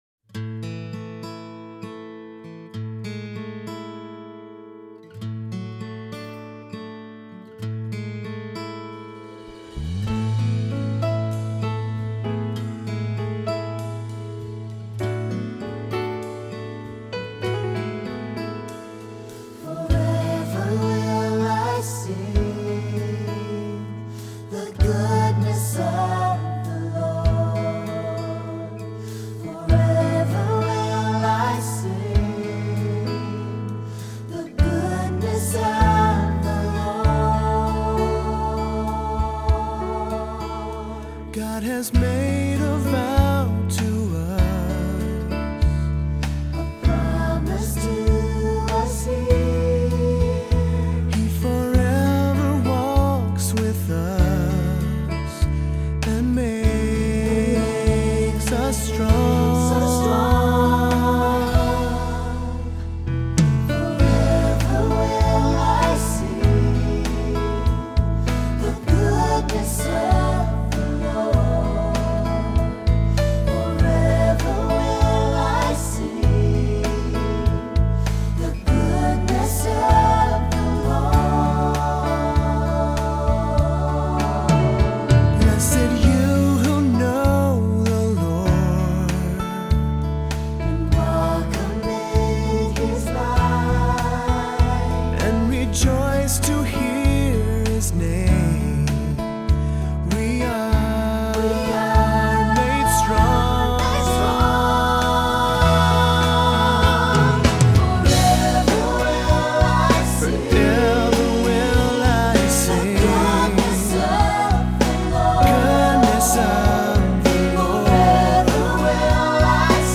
Voicing: Two-part equal; Cantor; Assembly